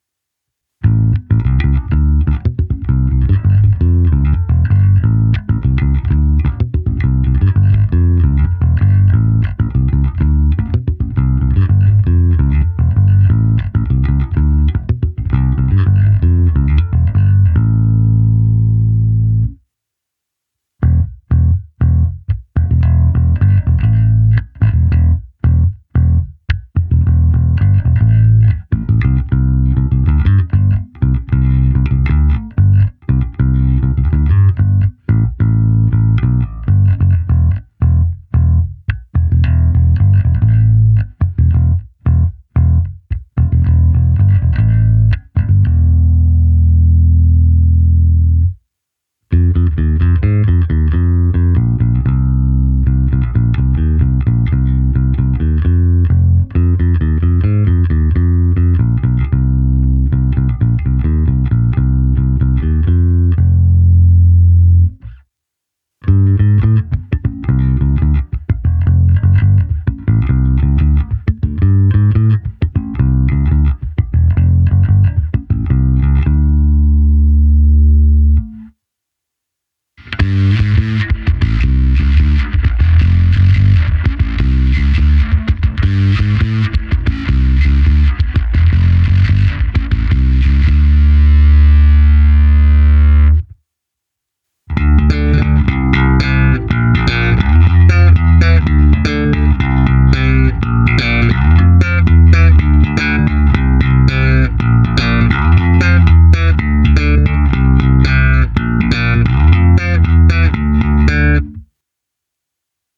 Baskytara má dostatek středů umožňujících jí se prosadit v kapele a zároveň tmelit zvuk.
Nahrávka se simulací aparátu, kde bylo použito i zkreslení a hra slapem, hráno na oba snímače